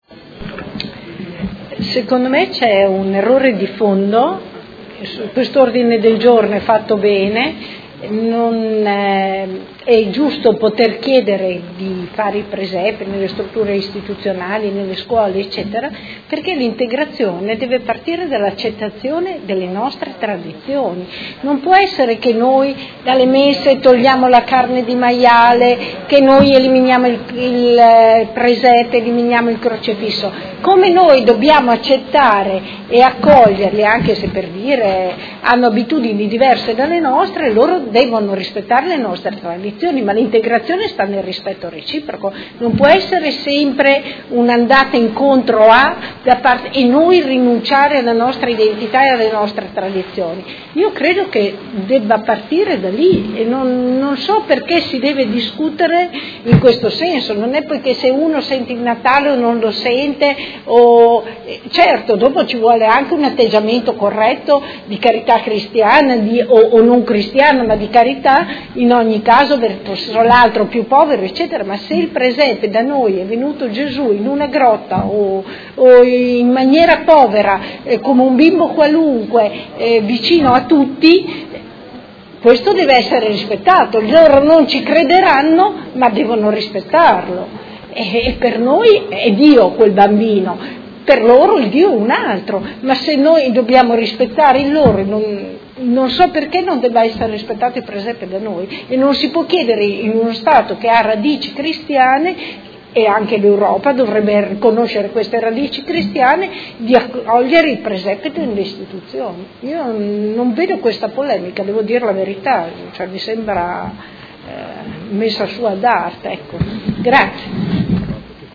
Luigia Santoro — Sito Audio Consiglio Comunale